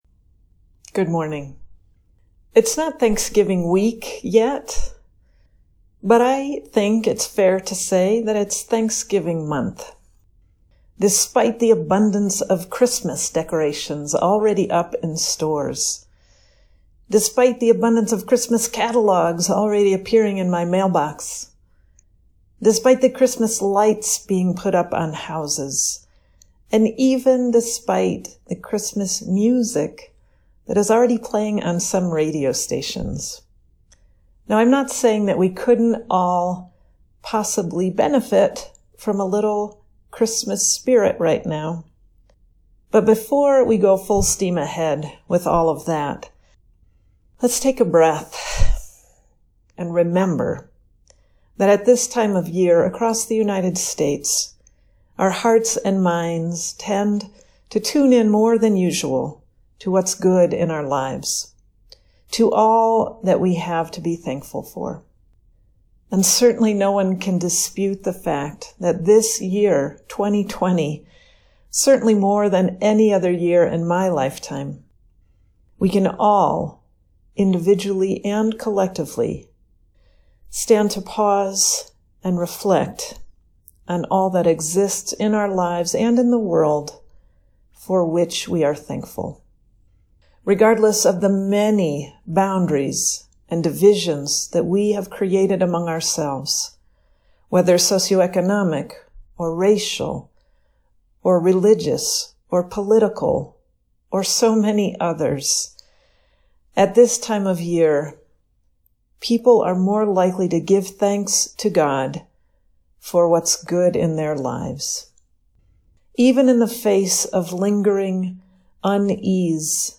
WRPC Sermons: 2020